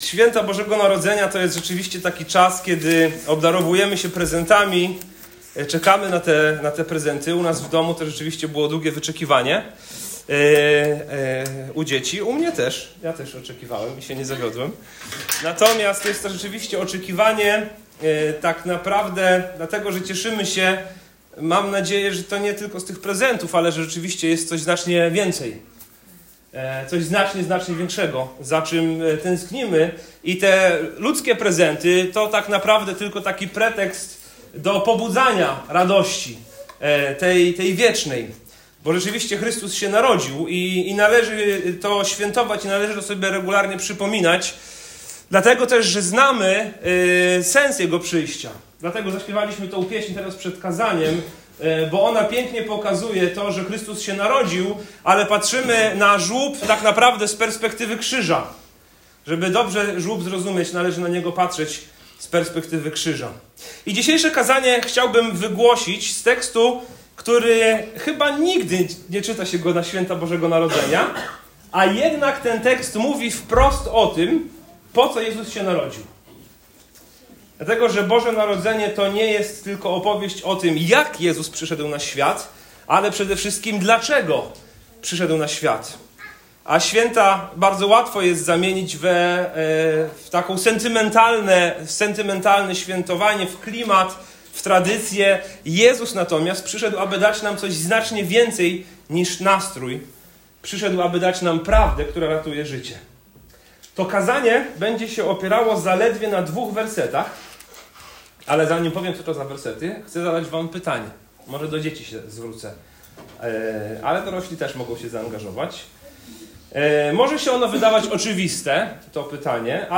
Boże Narodzenie widziane z perspektywy sali sądowej Piłata – nie sentymentalnie, ale prawdziwie. To kazanie konfrontuje świąteczną kulturę z pytaniem Jezusa o prawdę i wzywa do decyzji, przed którą nie da się uciec.